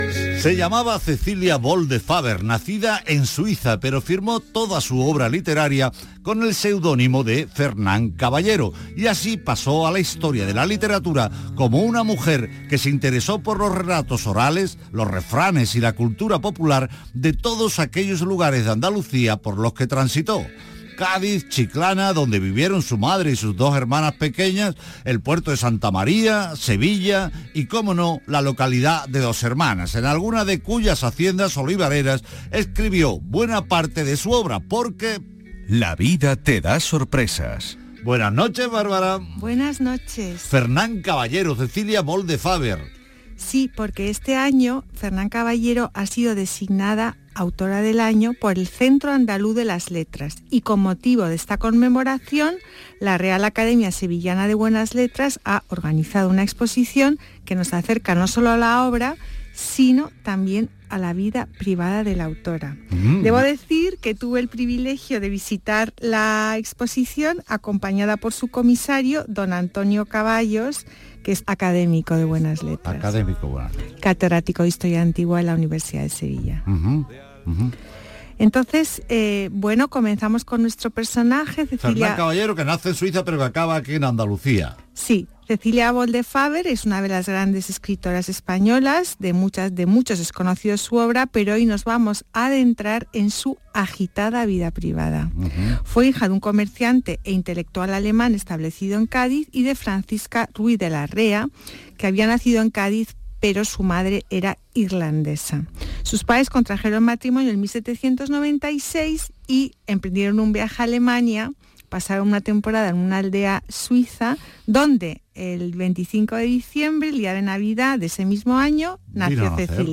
Aquí os dejo mi intervención en el programa de Radio Andalucía Información, «Patrimonio andaluz» del día 30/10/2022